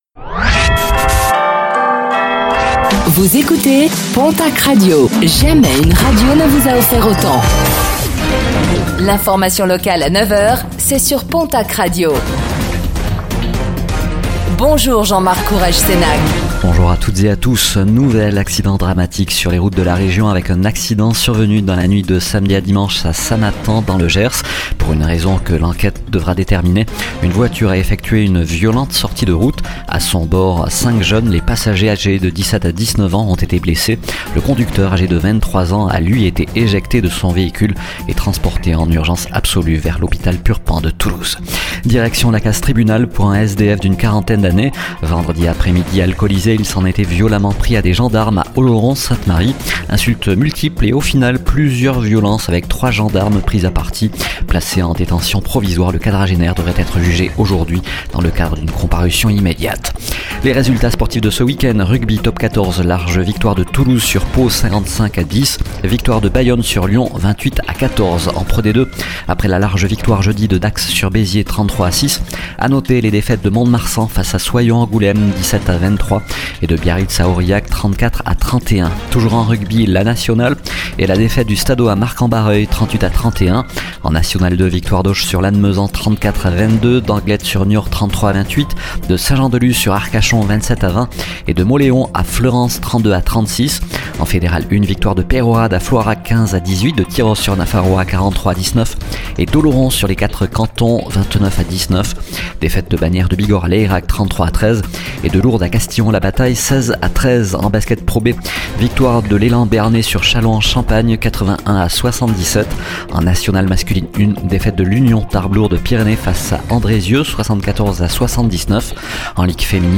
Infos | Lundi 31 mars 2025